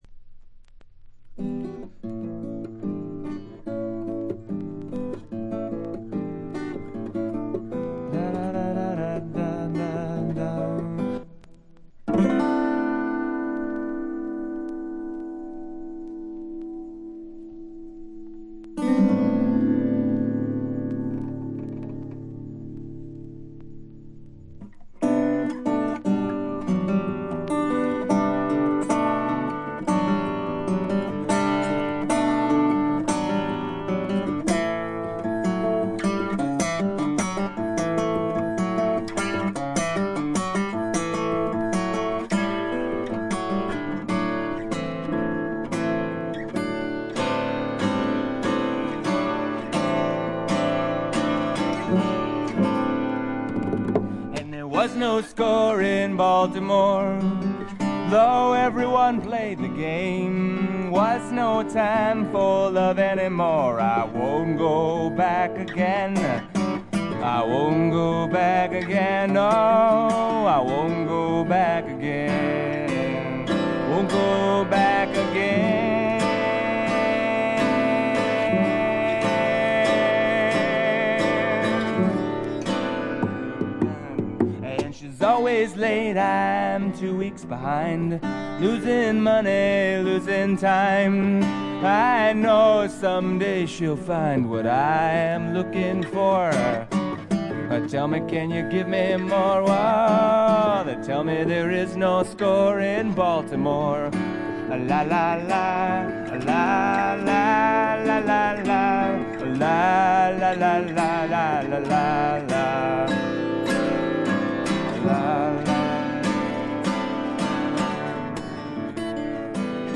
vocals and acoustic guitar
bongos
violin
piano
dobro
bass
drums.